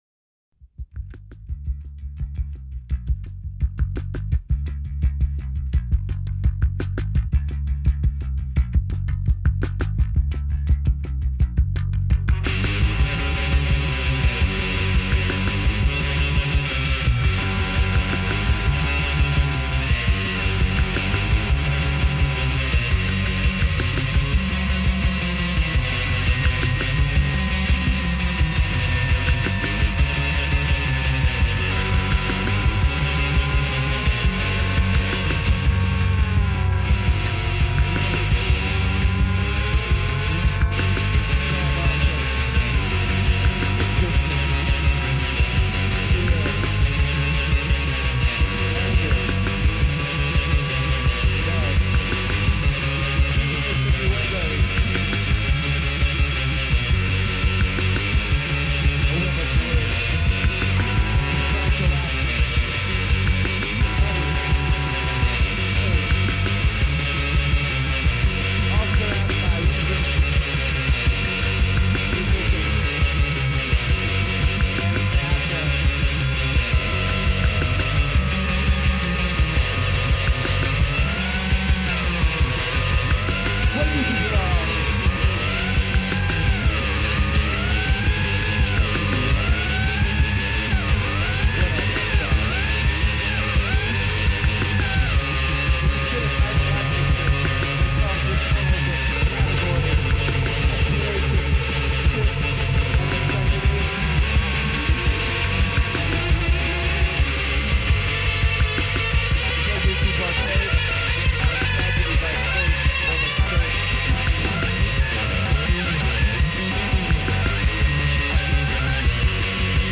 in the land of Tucson punk rock